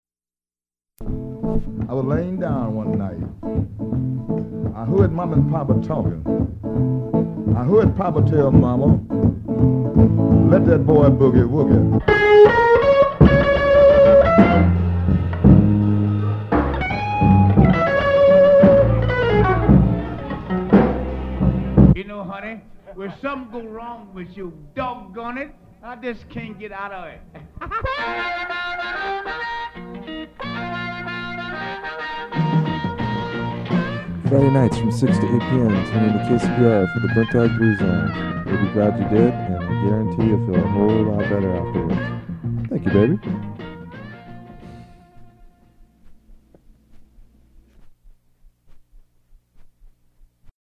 Form of original Audiocassette